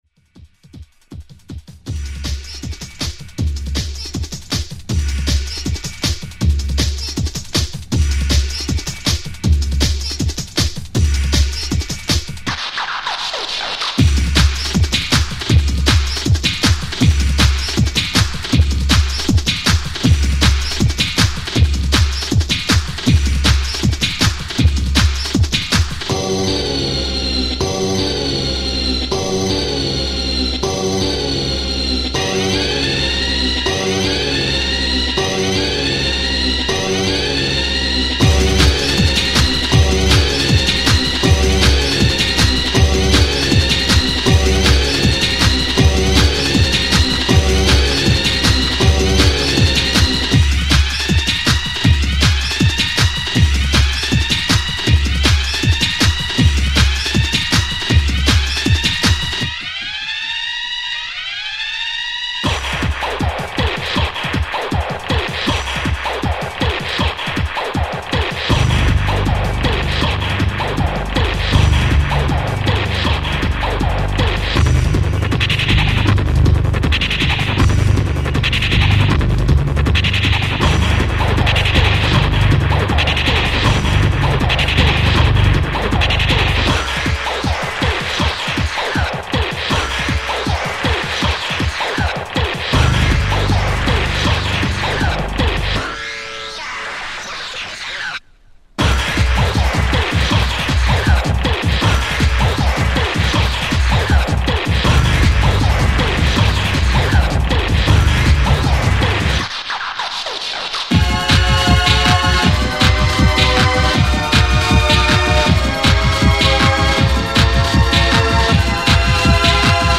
UK Hardcore